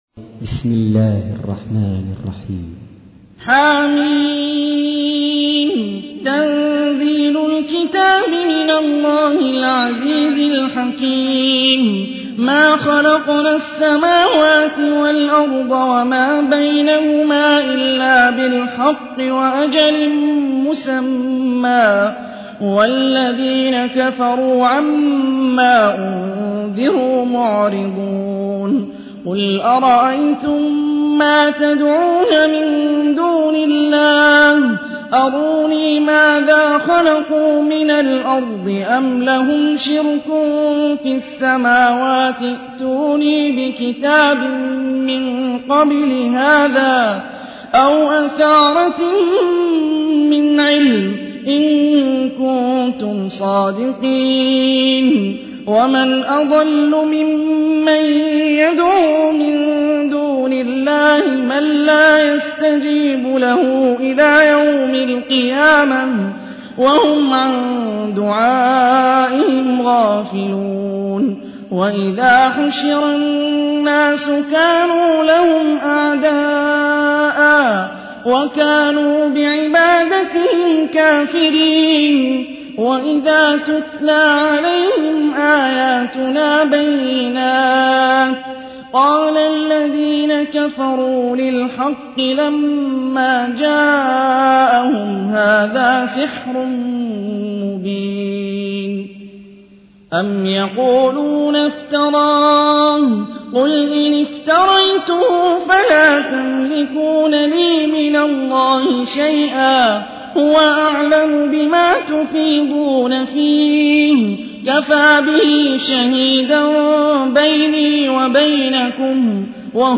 تحميل : 46. سورة الأحقاف / القارئ هاني الرفاعي / القرآن الكريم / موقع يا حسين